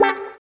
137 GTR 11-R.wav